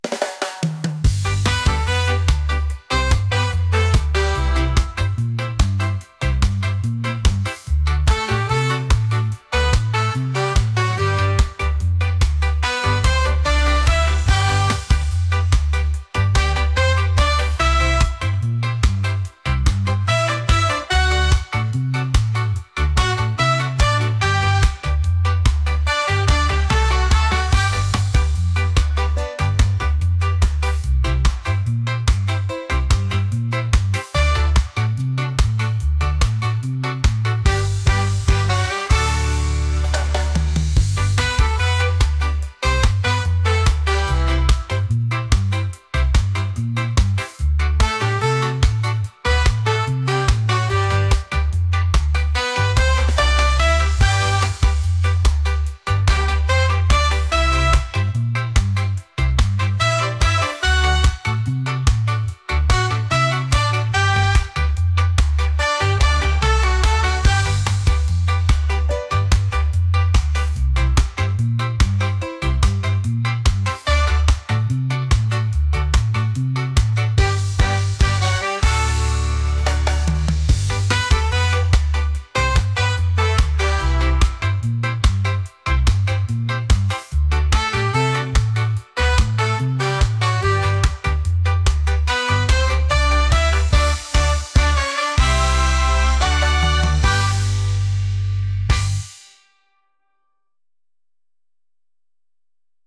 catchy | upbeat | reggae